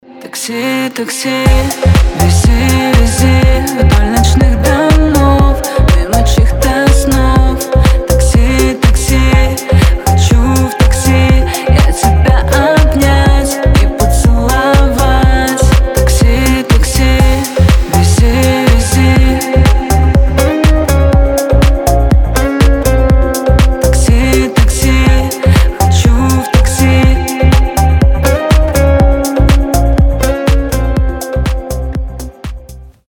поп , красивый мужской голос